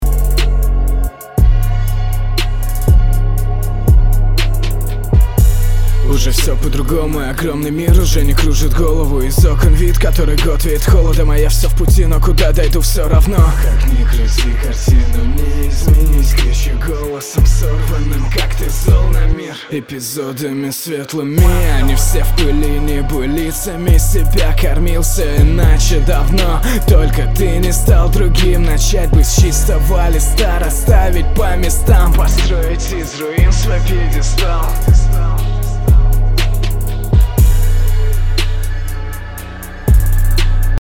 До 0:13 всё хорошо было, потом слегка сник, более тянучий, нудный флоу пошёл. И интонации на "ударах" в бит (например, на 0:13 на "всё равно") слегка кривые, не дотягиваешь и звучит не очень уверенно + ощущение, что немного не попадаешь ритмически.